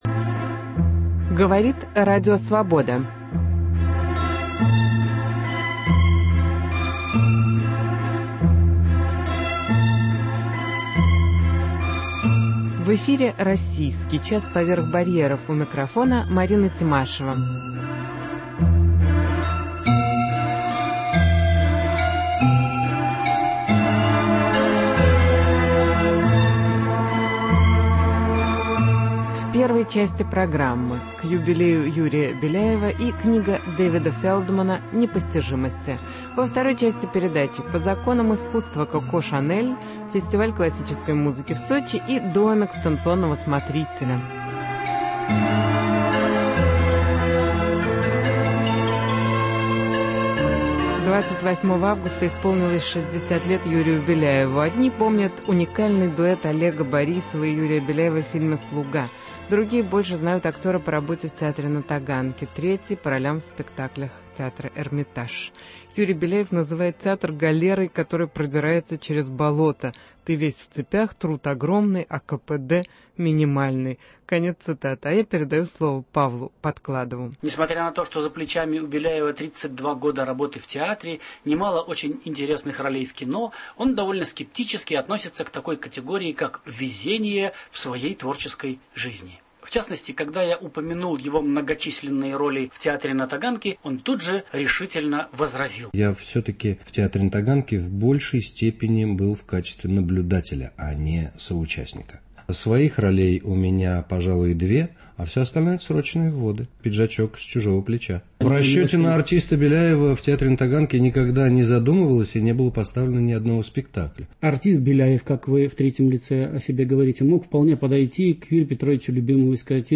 Интервью с Юрием Беляевым - к юбилею артиста